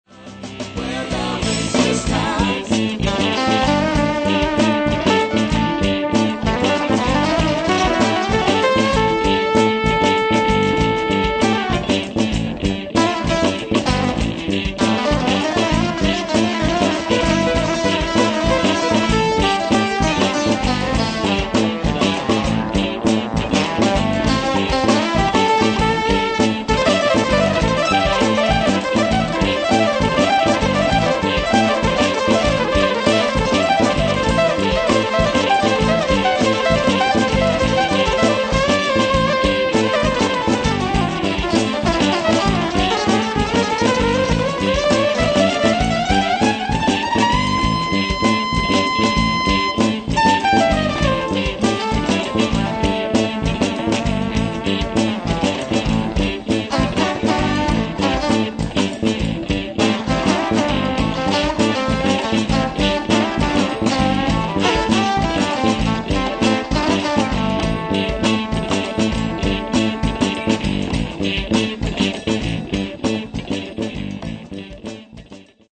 Mixed bag of solo excerpts    [ top ]
guitar/synth